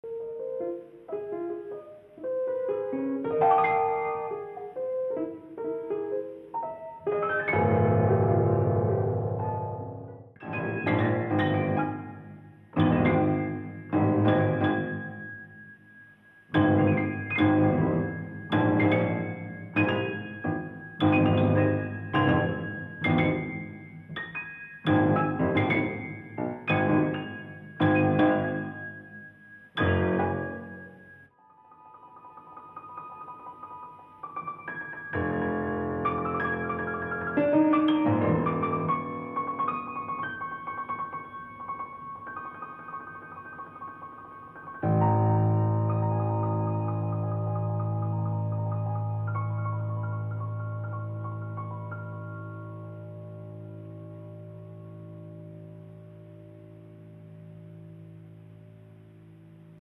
concerto dal vivo
audio 44kz stereo